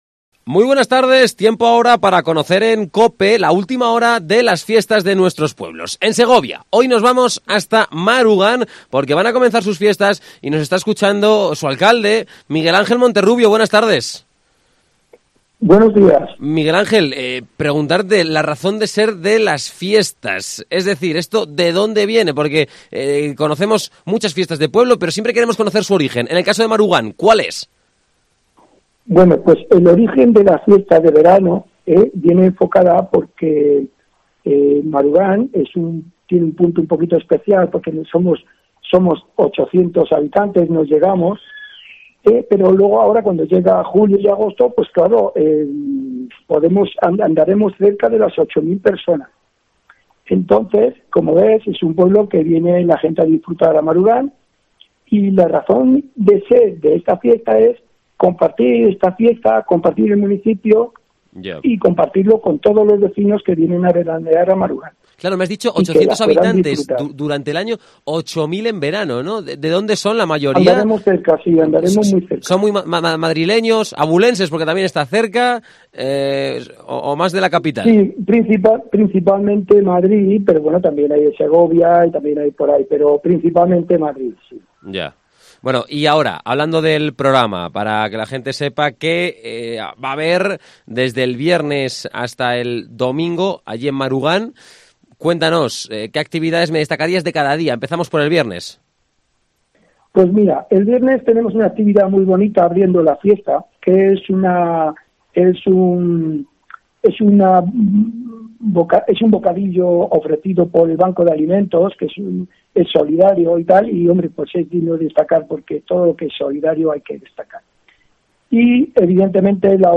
Entrevista a Miguel Ángel Monterrubio Alcalde de Marugán.